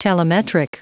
Prononciation du mot telemetric en anglais (fichier audio)